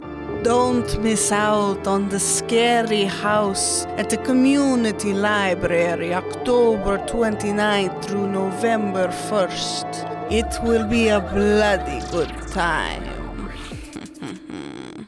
A radio spot informing GTMO residents of the haunted house Oct. 29 - Nov. 1.